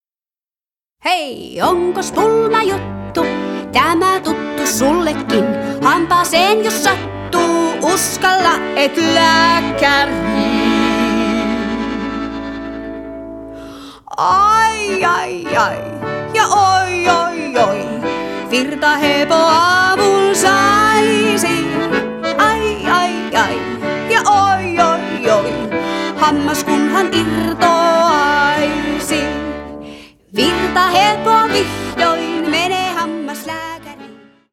jenkkalaulelma